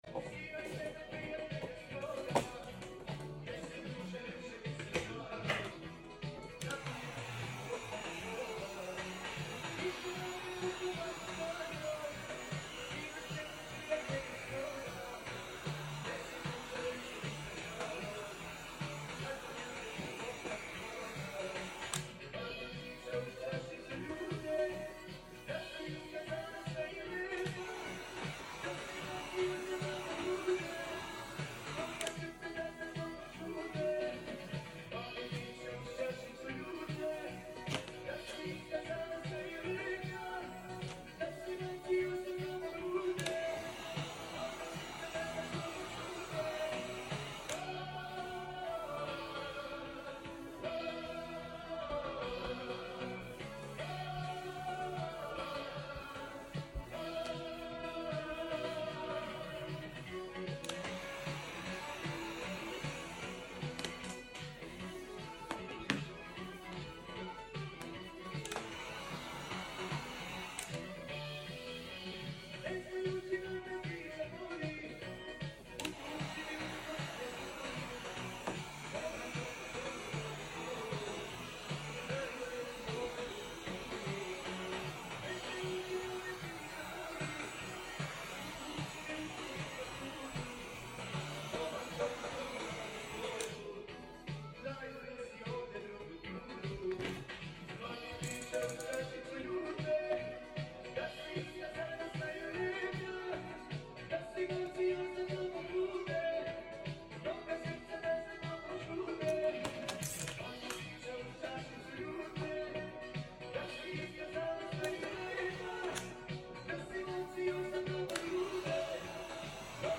🎯 ASMR satisfaction level: 100 sound effects free download